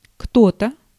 Ääntäminen
IPA : /ˈsʌmwʌn/